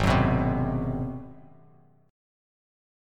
Listen to G#7#9 strummed